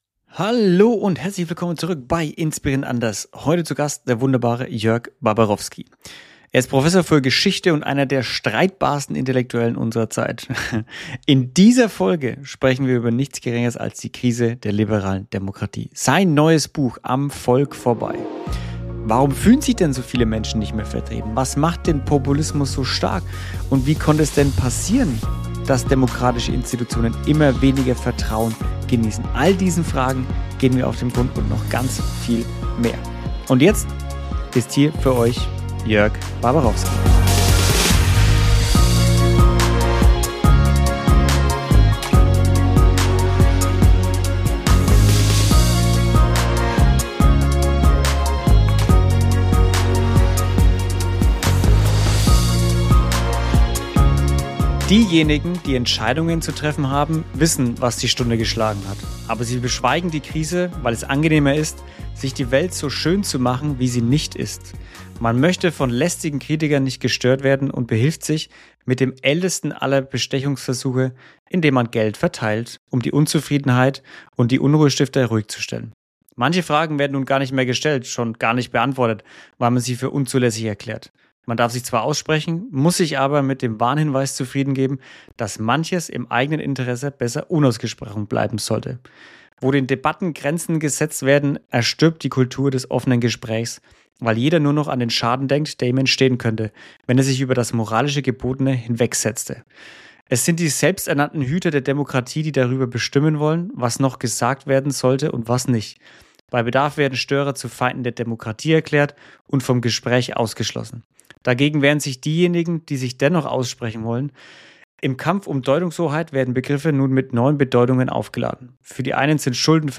In dieser Folge zu Gast: Prof. Dr. Jörg Baberowski – Historiker, Publizist und scharfer Kritiker des gegenwärtigen politischen Diskurses in Deutschland.